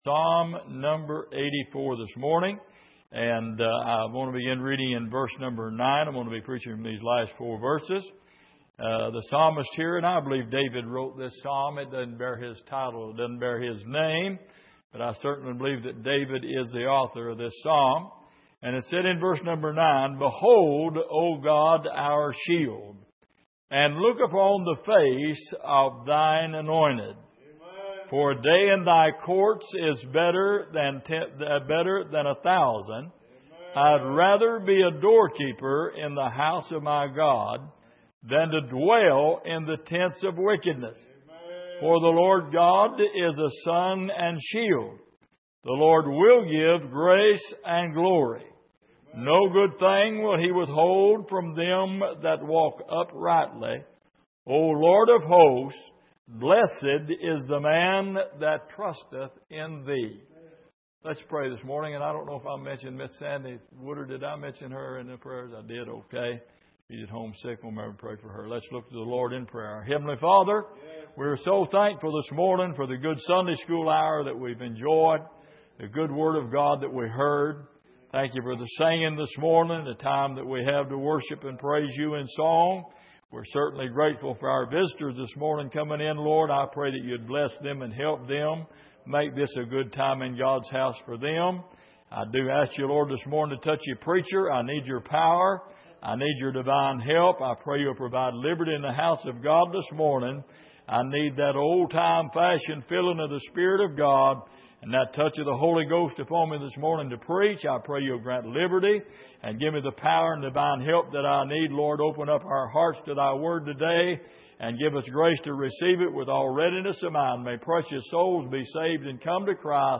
Passage: Psalm 84:9-12 Service: Sunday Morning